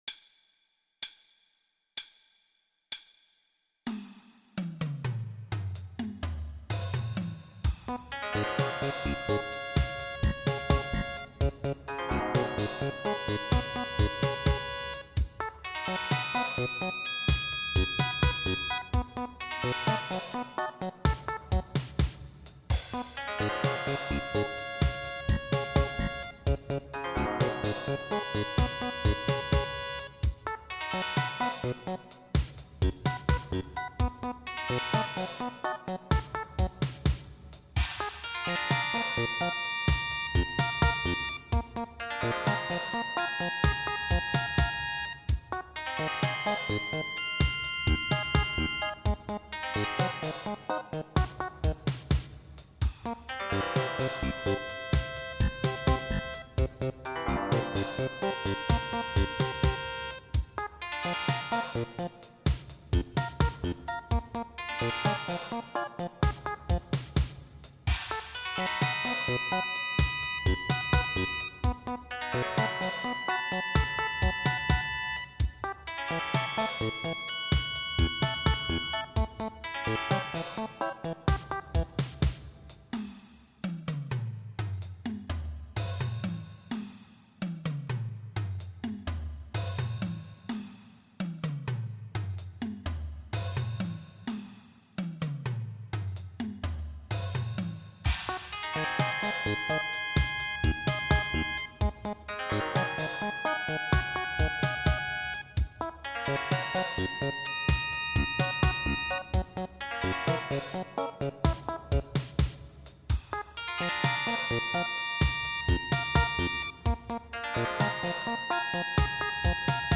16beat